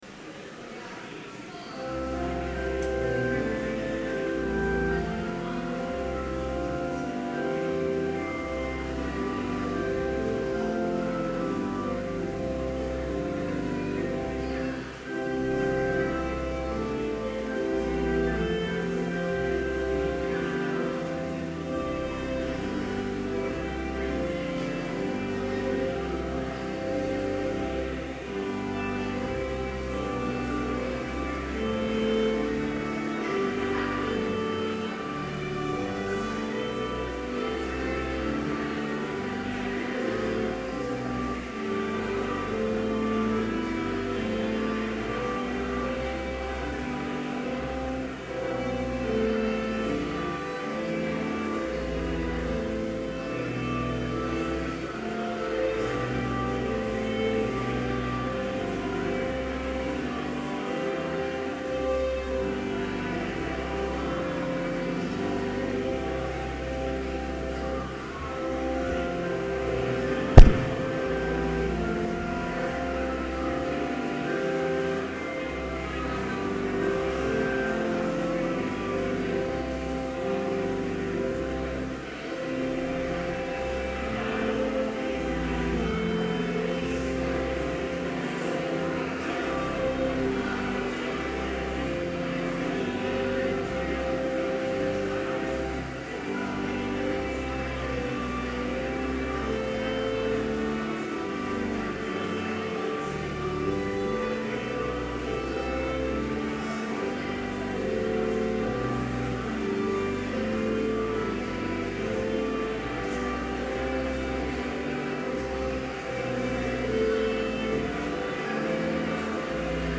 Complete service audio for Chapel - August 26, 2011